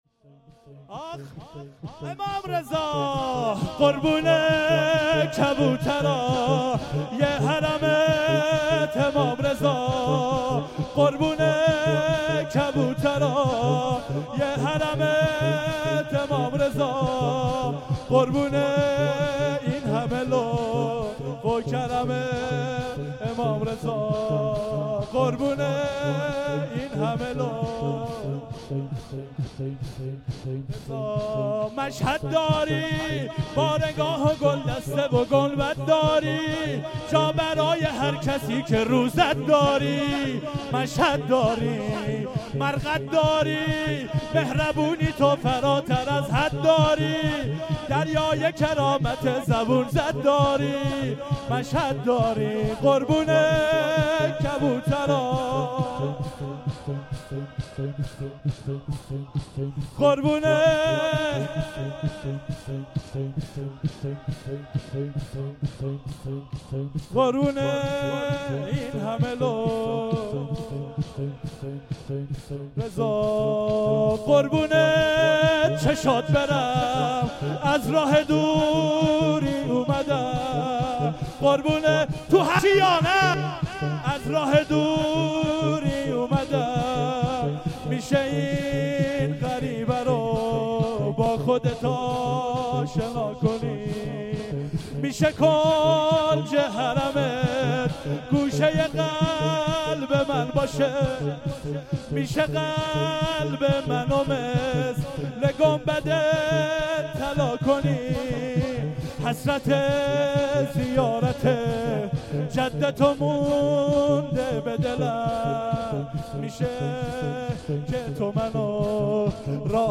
شور2